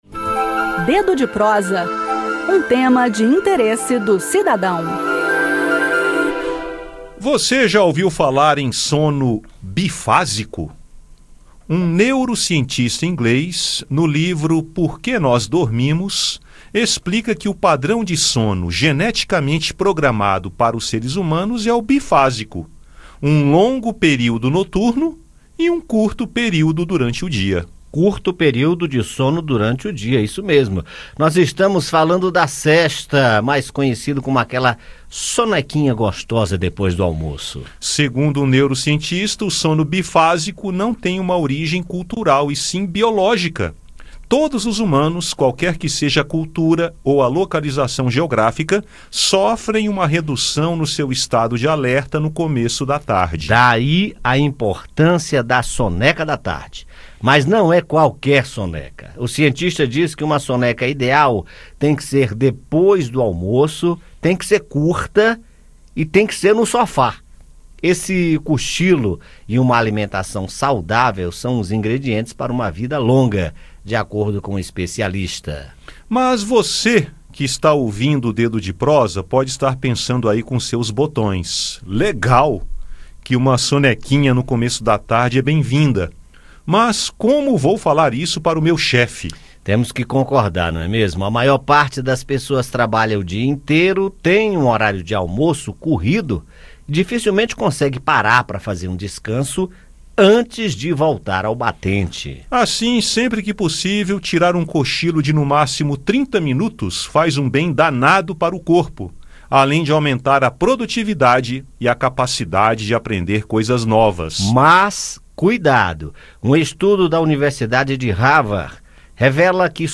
A “sesta”, aquela soneca depois do almoço, não é apenas um hábito cultural e sim biológico. Ouça mais no bate-papo.